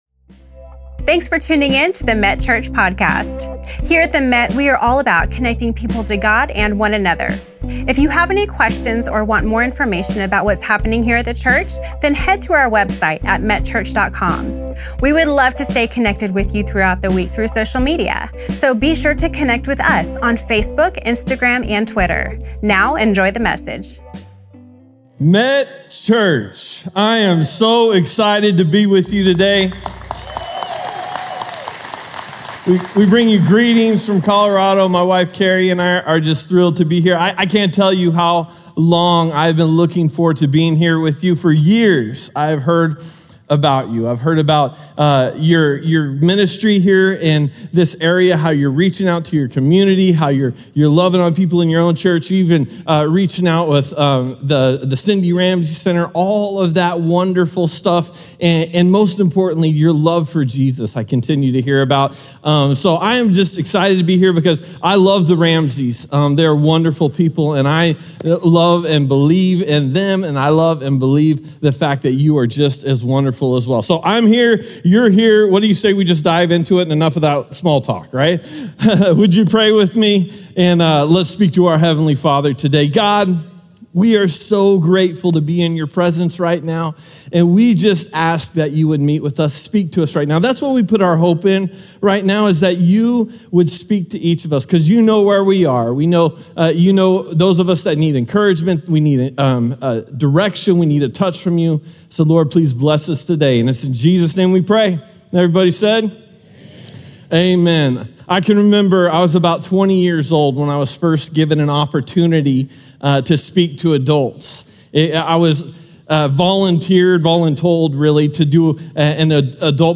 Special guest